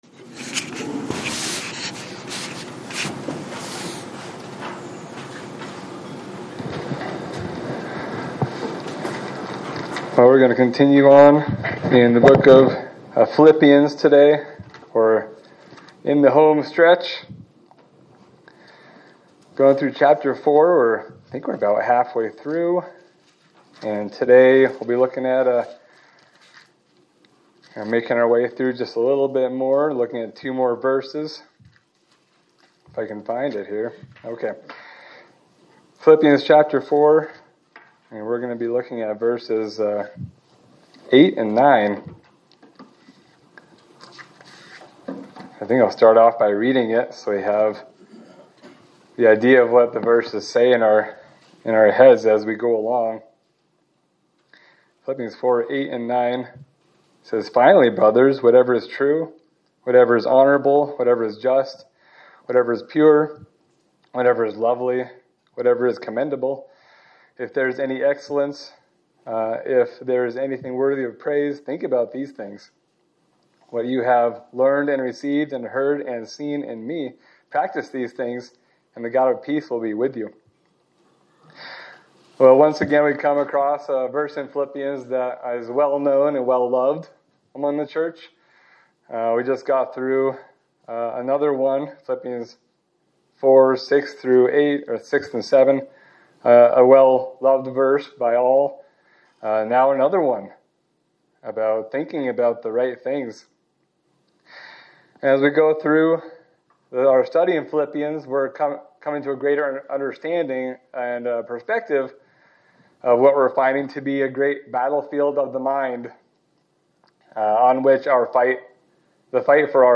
Sermon for March 1, 2026